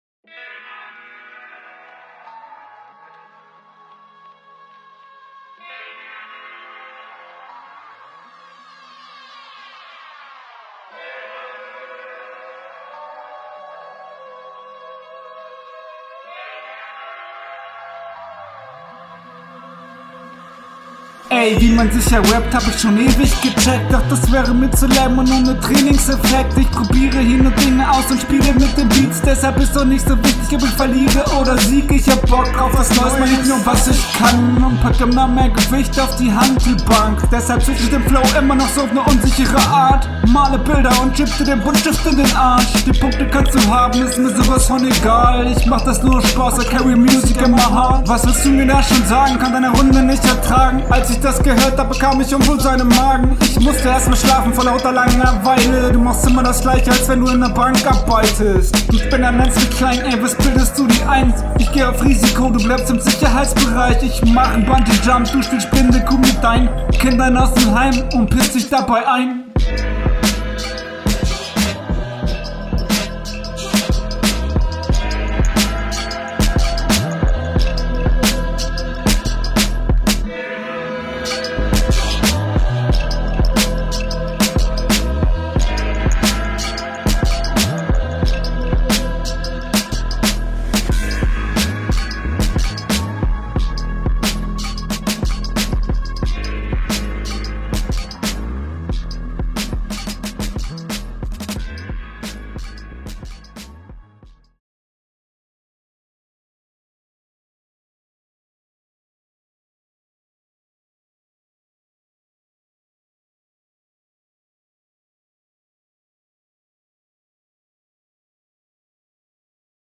Kommst eindeutig schlechter auf dem Beat.